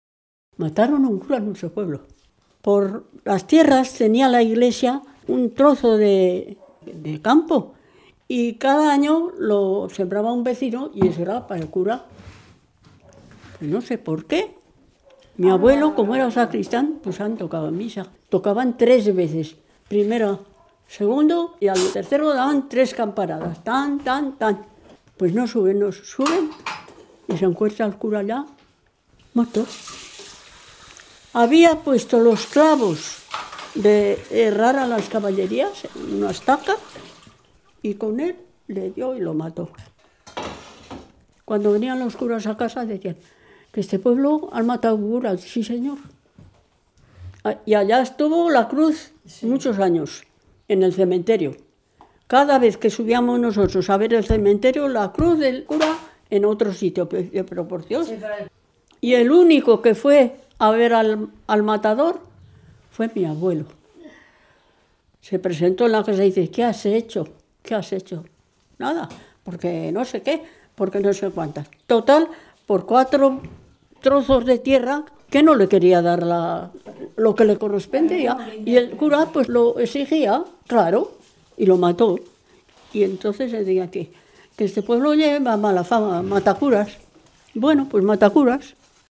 Clasificación: Dicterios
Lugar y fecha de recogida: Munilla, 11 de abril de 2004